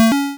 collect_a.wav